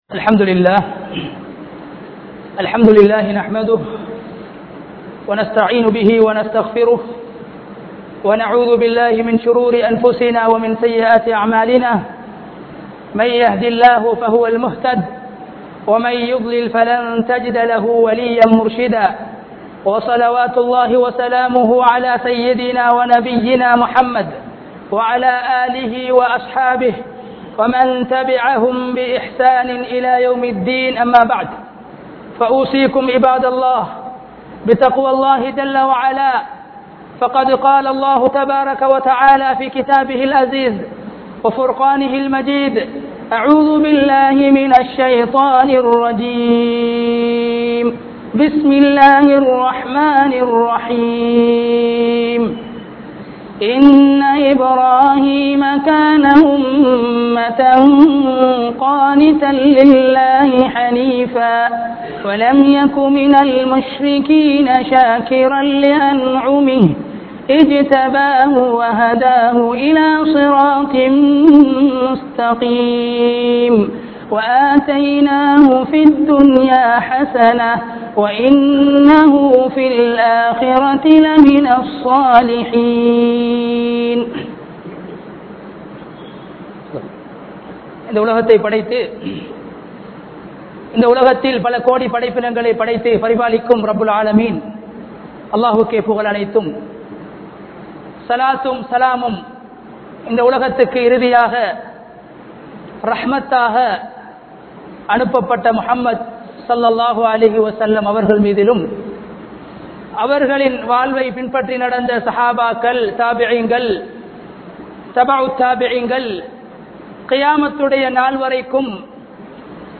Al Quran Koorum Ibrahim Nabi (அல்குர்ஆன் கூறும் இப்ராஹிம் நபி) | Audio Bayans | All Ceylon Muslim Youth Community | Addalaichenai
Yathianthoatta, Town Jumua Masjidh 2017-08-04 Tamil Download